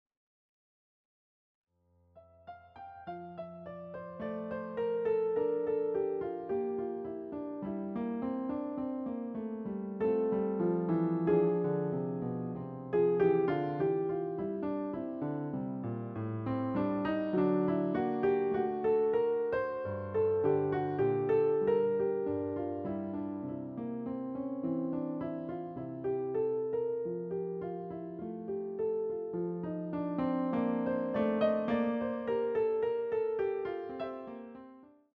CD quality digital audio
using the stereo sampled sound of a Yamaha Grand Piano.